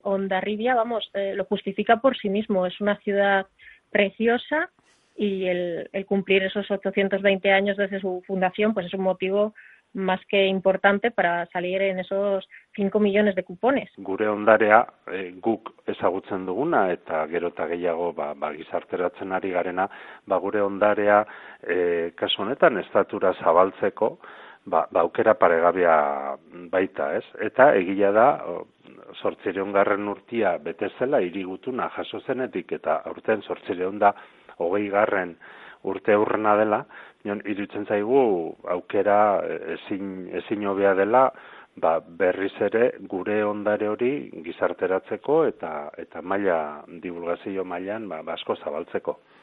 En la entrevista también ha estado presente el alcalde de Hondarribia, Txomin Sagarzazu, quien ha repasado la historia del municipio.